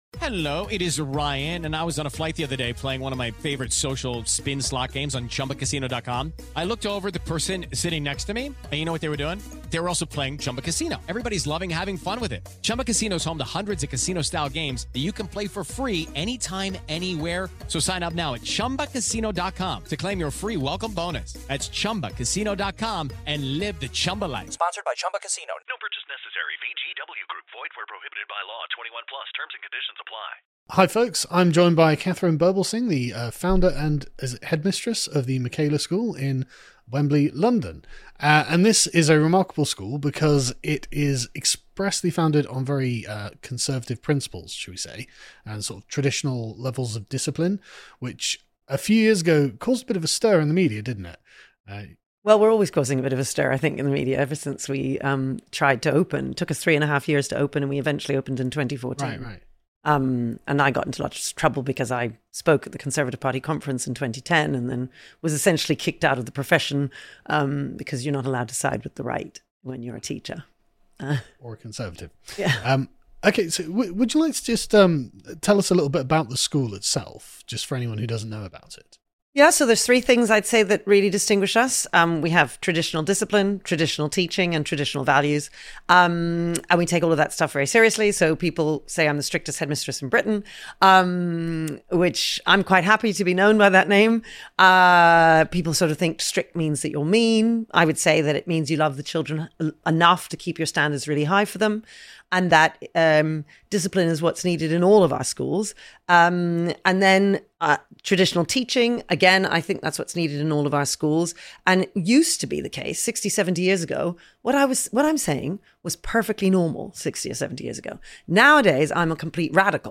Everyone Is Getting This Wrong | Interview with Katharine Birbalsingh CBE